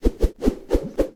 snd_reloading.ogg